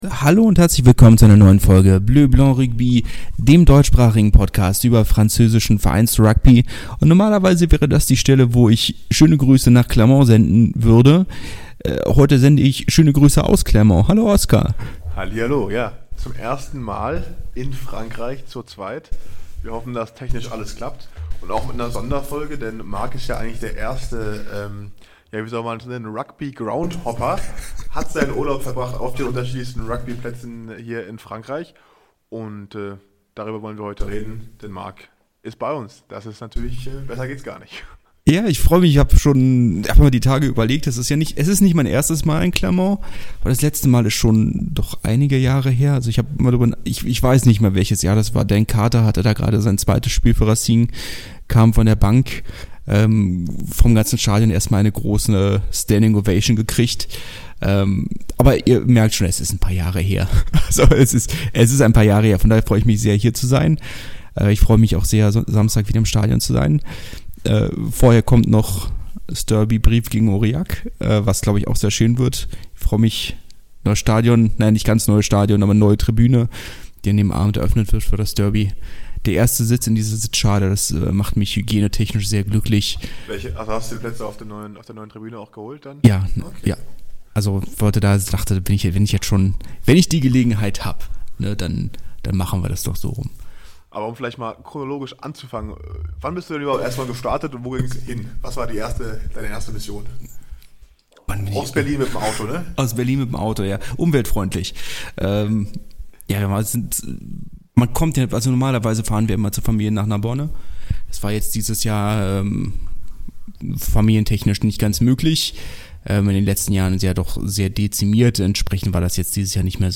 Mehr als die Hälfte des Rugby-Groundhoppings ist vorbei - Zeit für ein Resümee. Kleiner Disclaimer: Aufgrund der mobilen Aufnahme haben wir leider nicht die gewohnte Tonqualität.